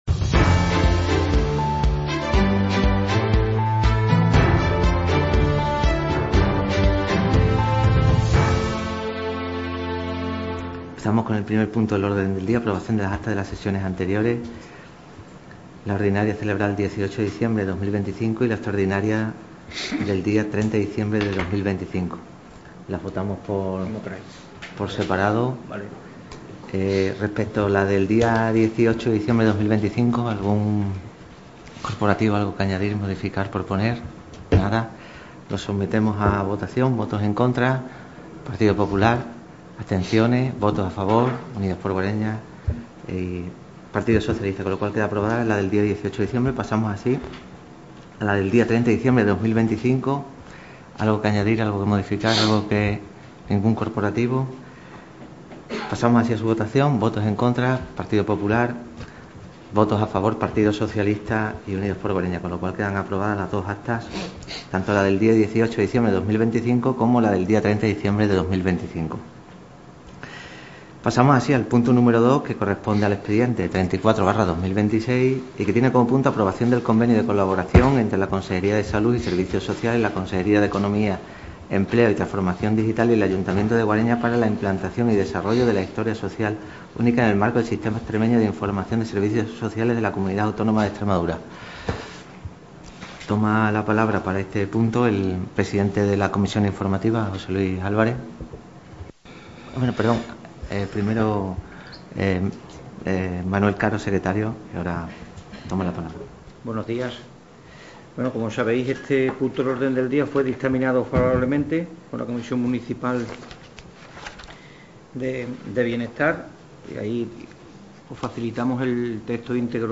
Pleno Ordinario 20.02.2026 (Audio íntegro) – Ayuntamiento de Guareña
Sesión celebrada en el Ayuntamiento de Guareña.